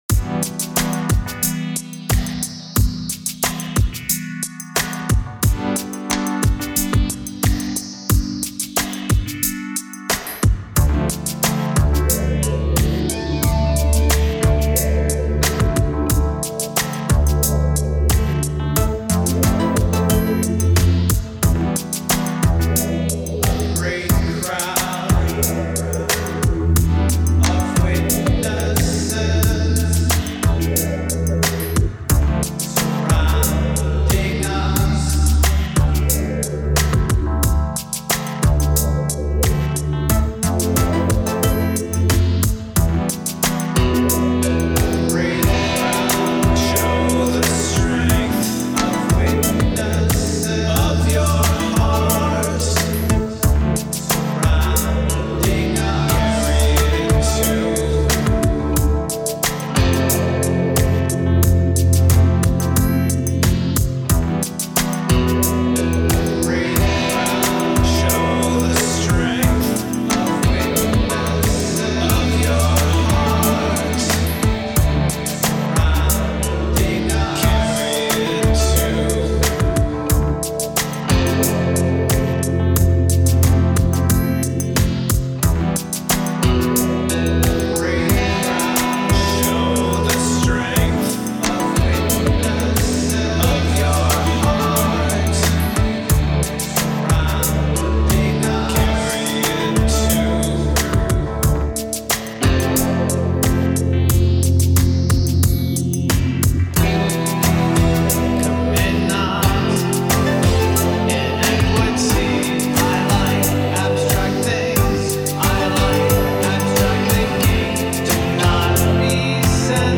I changed a mix a bit and Lofi’d some of the vocals
The guitars need work. There are 13 vocal tracks.
The opening sixteen bars could be extended as many as 4 total times, slowly evolving into the vocal part.